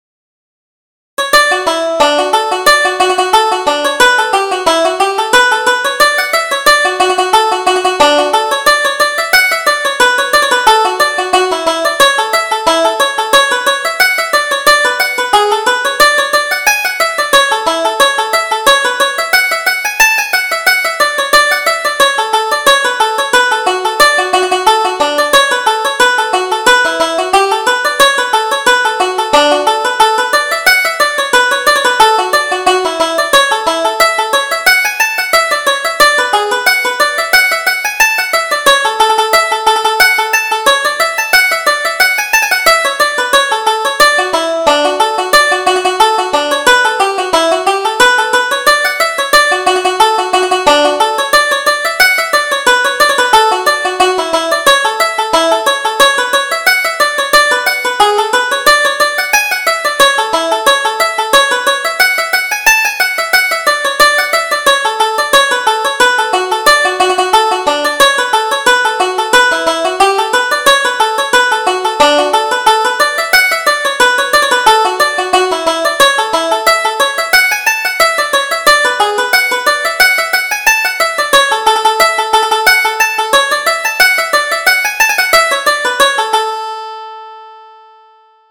Reel: The Gaelic Revival